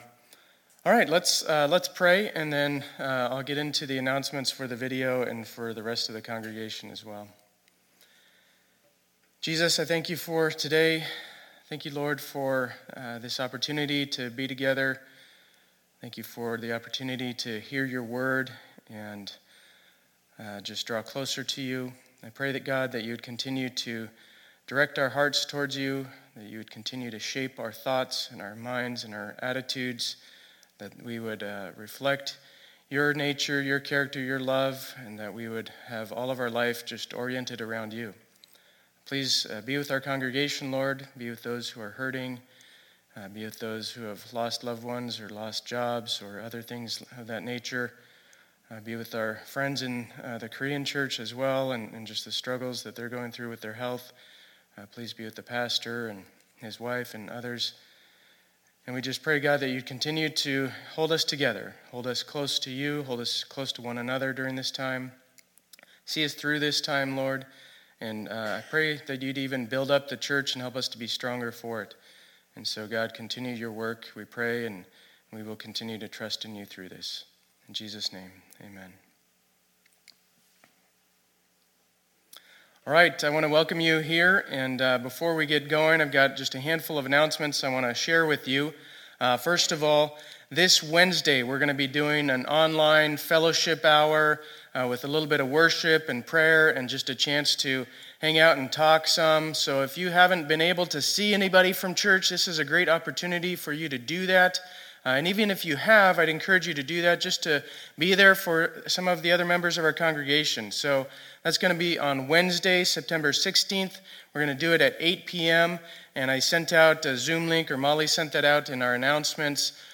2020-09-13 Sunday Service